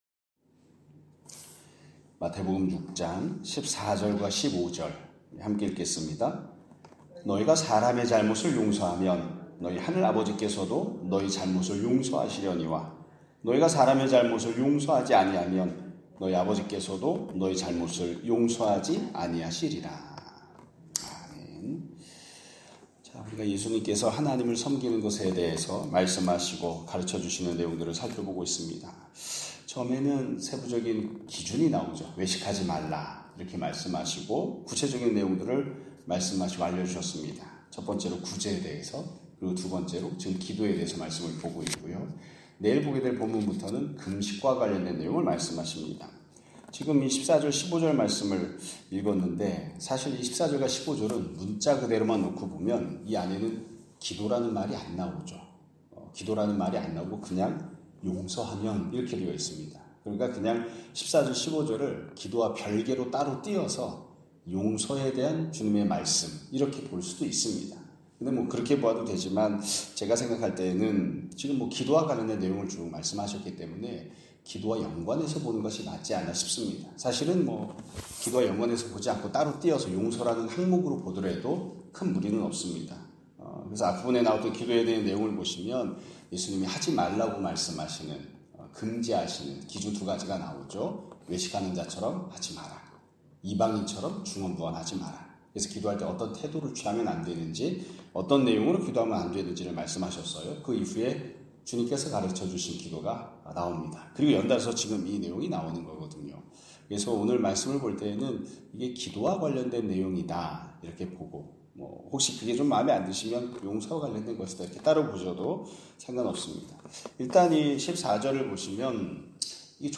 2025년 6월 16일(월요일) <아침예배> 설교입니다.